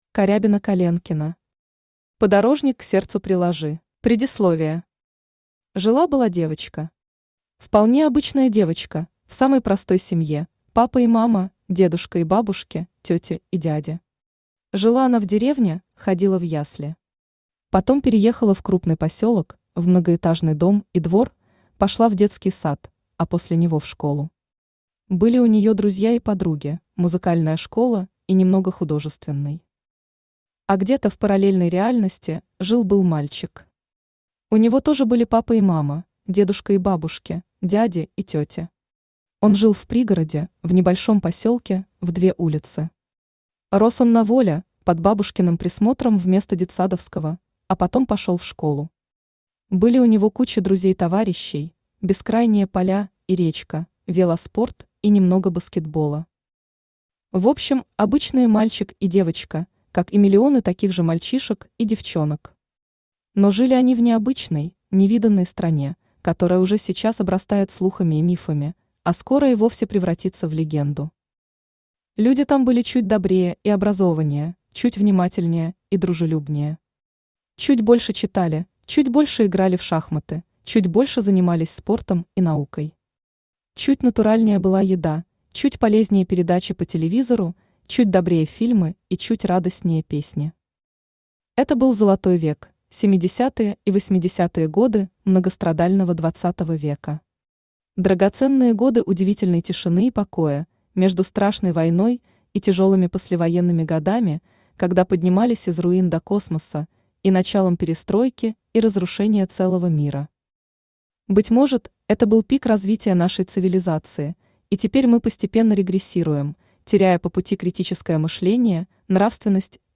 Аудиокнига Подорожник к сердцу приложи | Библиотека аудиокниг
Aудиокнига Подорожник к сердцу приложи Автор Карябина Коленкина Читает аудиокнигу Авточтец ЛитРес.